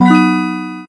notification.ogg